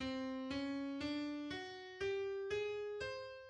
Tritone scale
The two-semitone tritone scale, C D D F G A, is a symmetric scale consisting of a repeated pattern of two semitones followed by a major third now used for improvisation and may substitute for any mode of the jazz minor scale.[14] The scale originated in Nicolas Slonimsky's book Thesaurus of Scales and Melodic Patterns through the "equal division of one octave into two parts," creating a tritone, and the "interpolation of two notes," adding two consequent semitones after the two resulting notes.[15] The scale is the fifth mode of Messiaen's list.